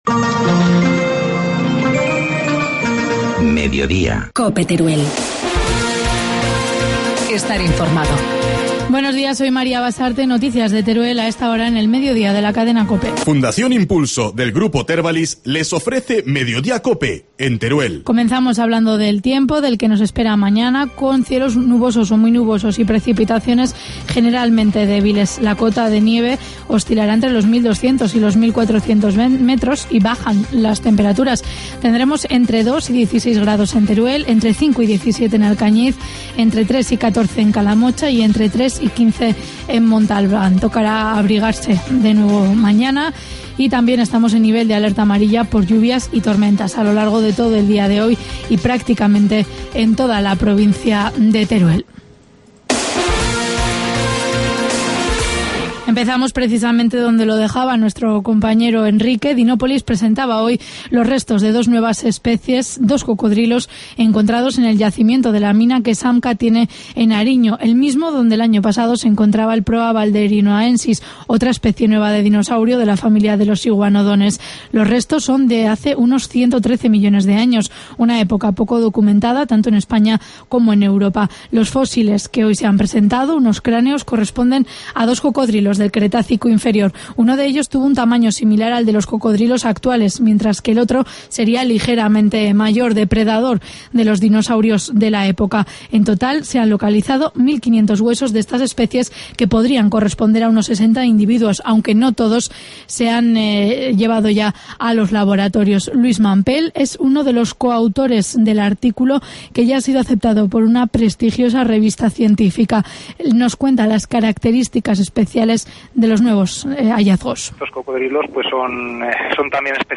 Informativo mediodía, miércoles 15 de mayo